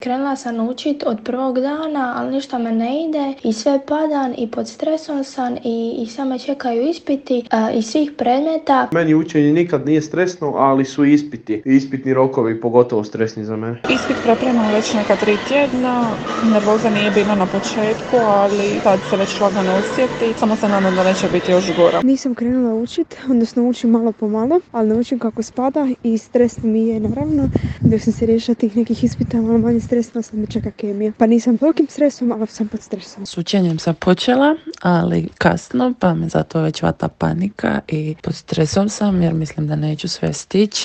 Mahom su nam odgovarali studenti.